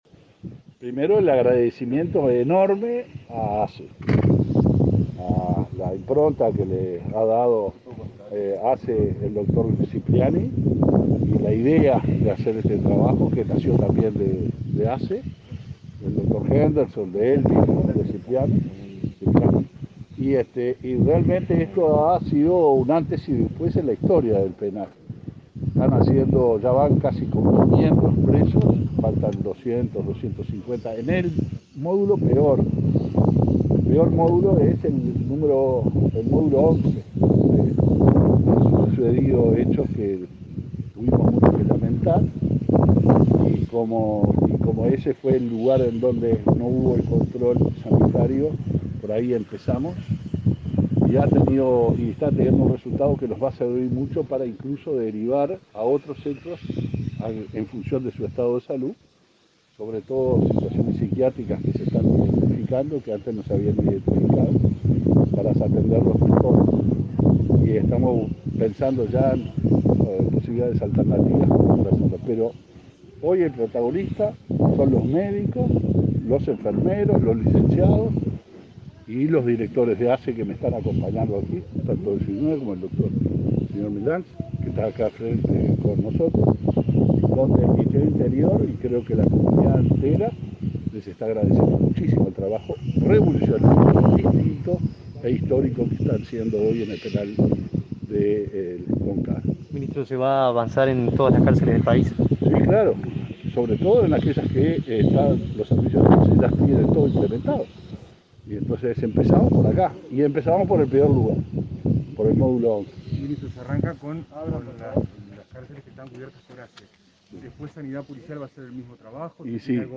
Declaraciones del ministro del Interior y del presidente de ASSE
El ministro del Interior, Luis Alberto Heber, y el presidente de ASSE, Leonardo Cipriani, dialogaron con la prensa tras visitar el módulo 11 del